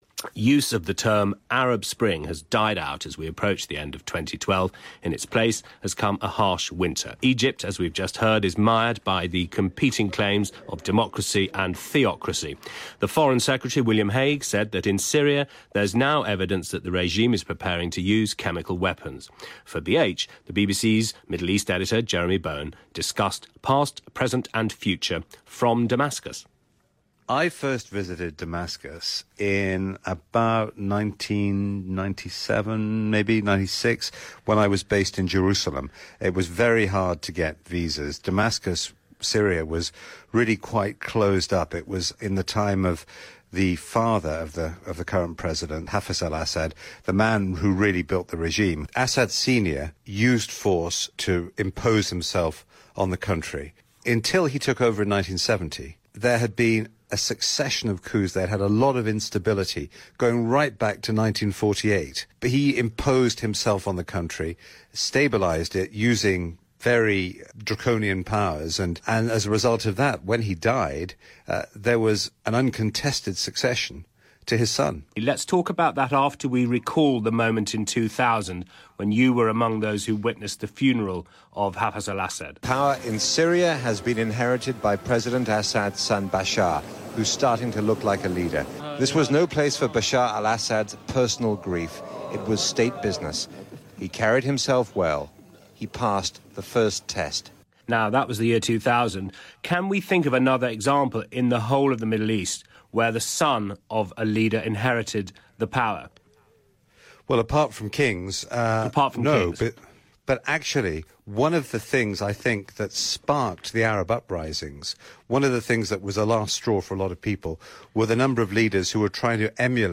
Jeremy Bowen in Damascus on BBC Radio 4 Broadcasting House